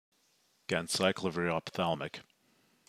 Pronounce: